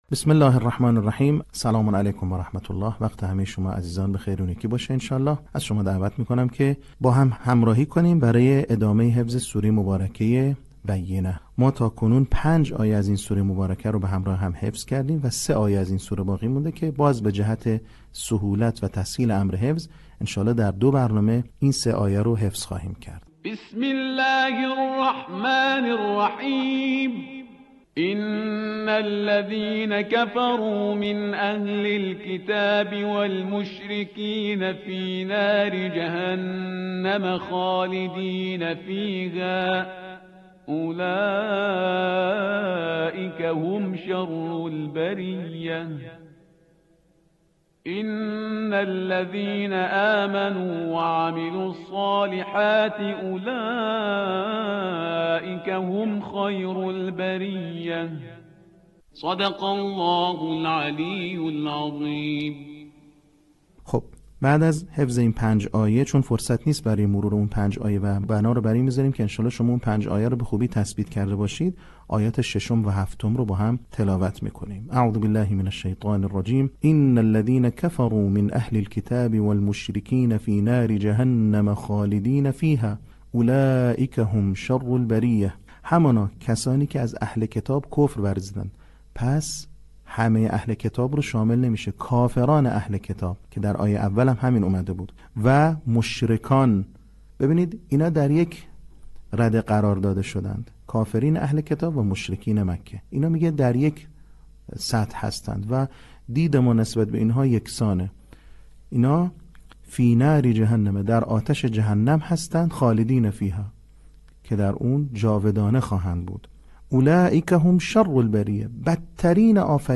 صوت | بخش سوم آموزش حفظ سوره بینه
به همین منظور مجموعه آموزشی شنیداری (صوتی) قرآنی را گردآوری و برای علاقه‌مندان بازنشر می‌کند.